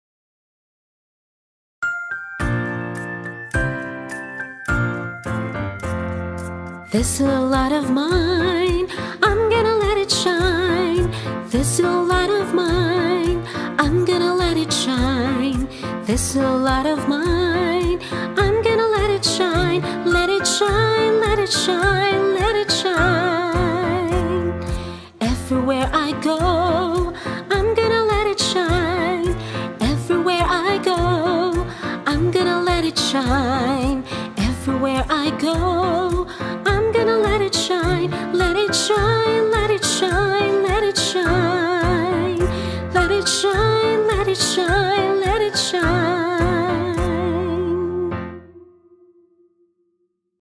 Music & Vocals Video Welcome (Youth)